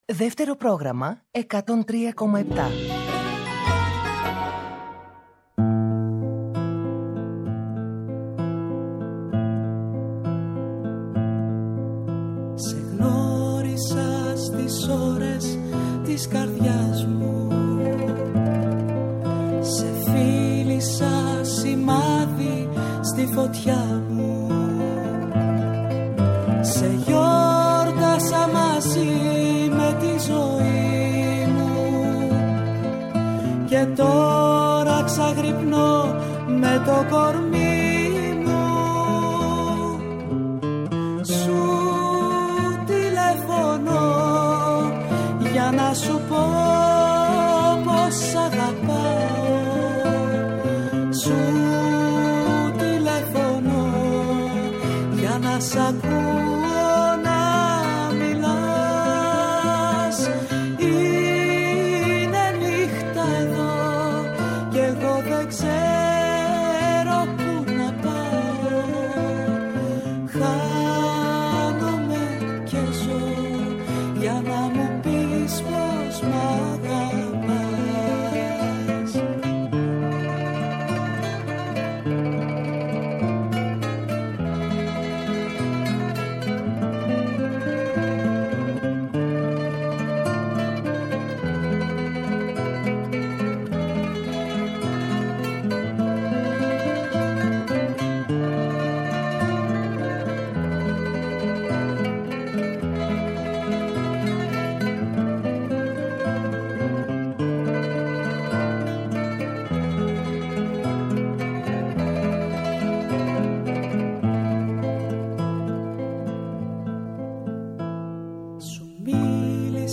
Τα τραγούδια της παρέας και πάντα το καινούργιο τραγούδι της ημέρας! Παλιά τραγούδια που κουβαλάνε μνήμες αλλά και νέα που πρόκειται να μας συντροφεύσουν. Κρατάει χρόνια 18 αυτή η παρέα και συνεχίζει ακάθεκτη!